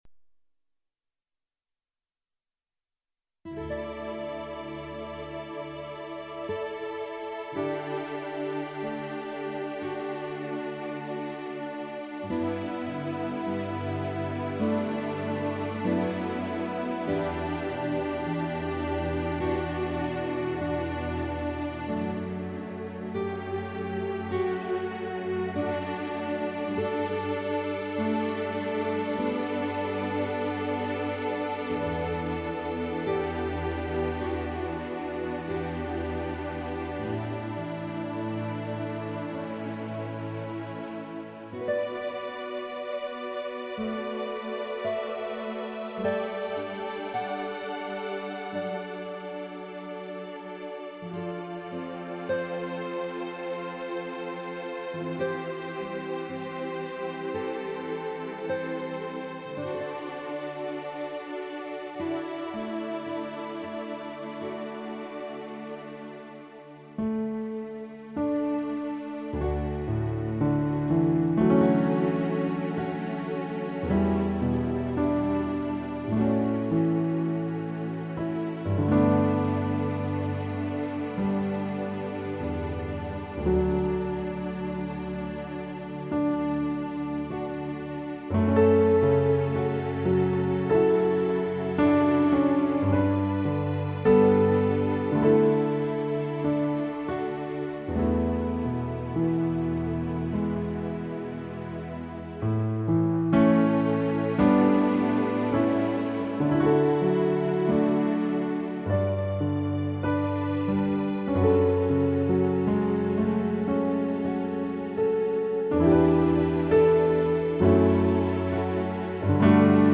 The first project is simply instrumental Catholic/Christian standards as music for meditation to use during Lent and Holy Week.